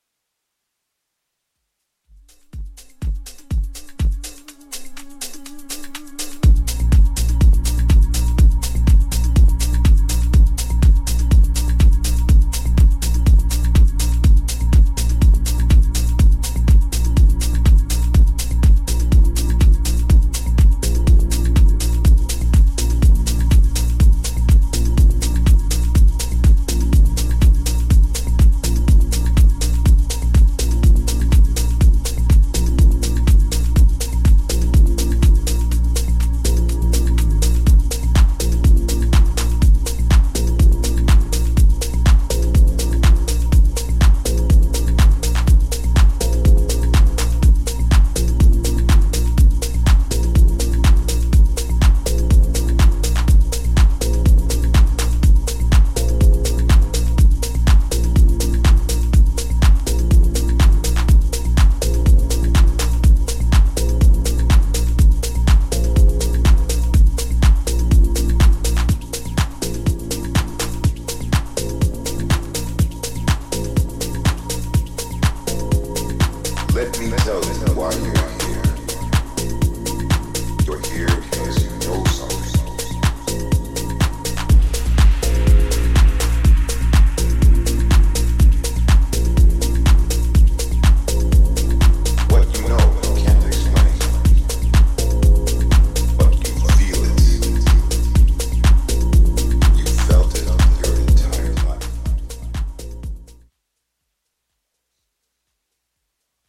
ジャンル(スタイル) TECH HOUSE / MINIMAL HOUSE